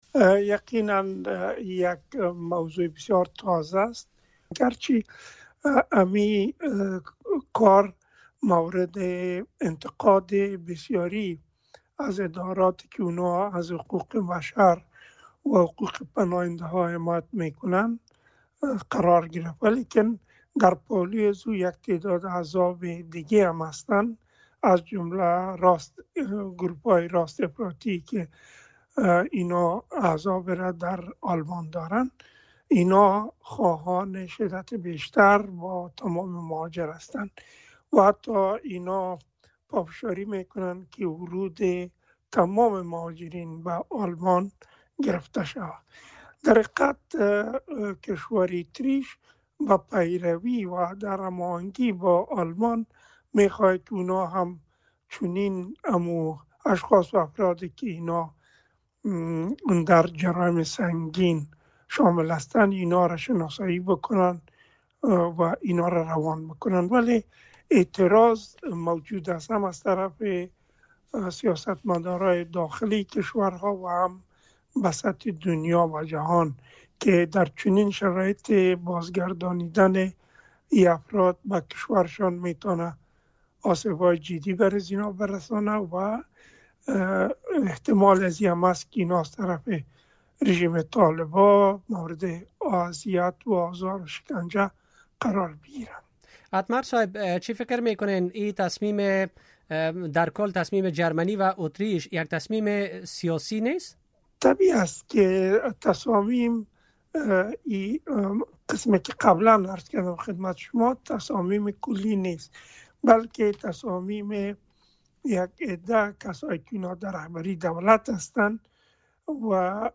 مصاحبه | اخراج پناه‌جویان افغان تأثیر منفی بر درخواست‌های پناهندگی در اروپا خواهد داشت